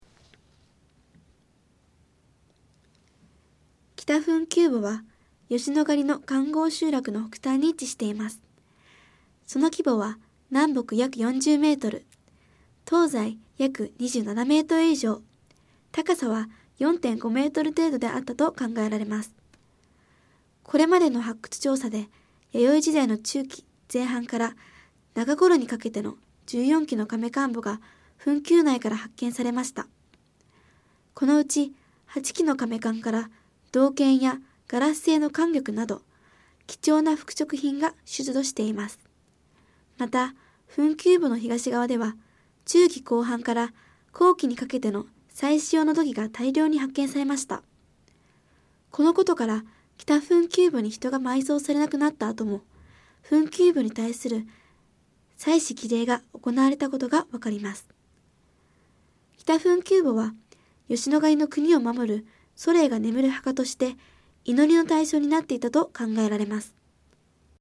音声ガイド 前のページ 次のページ ケータイガイドトップへ (C)YOSHINOGARI HISTORICAL PARK